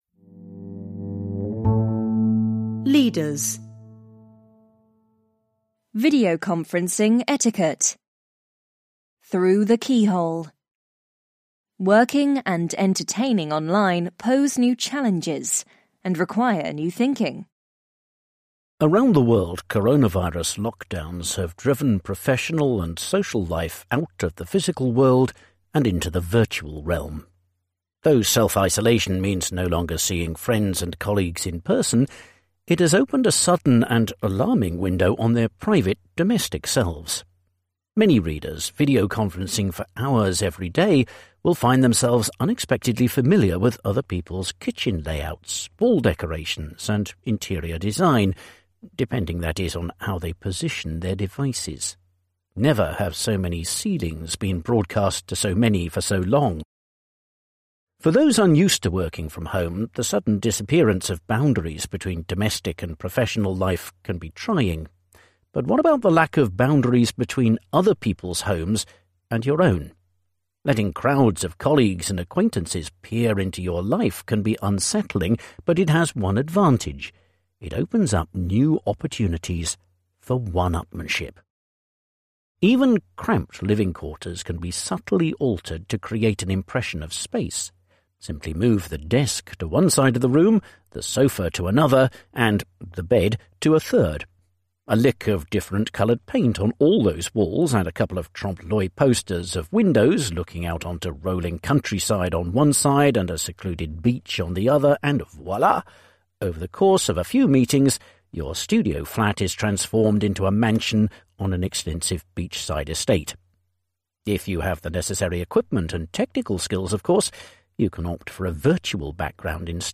Daher hier mal ein Artikel aus dem aktuellen Economist.